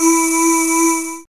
2406R SYNVOX.wav